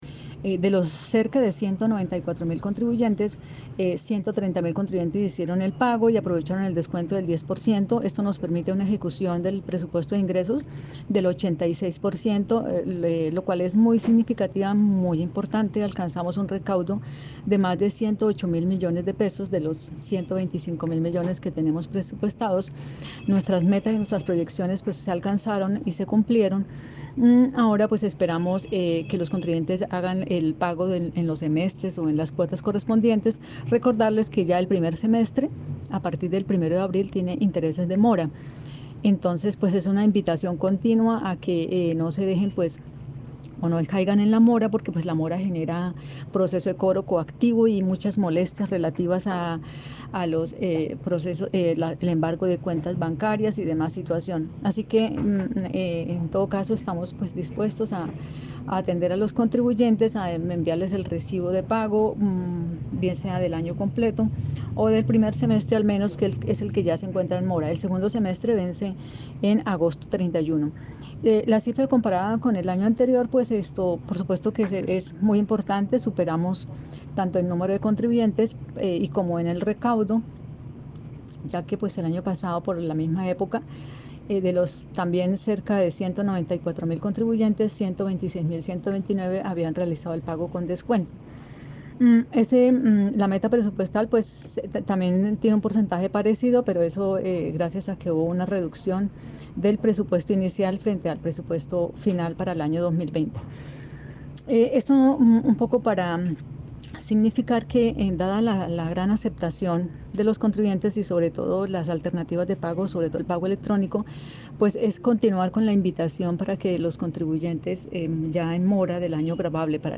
Descargue audio: Lina María Manrique, subsecretaria de Hacienda de Bucaramanga
LINA-MARIA-MANRIQUE-SUBSECRETARIA-DE-HACIENDA.mp3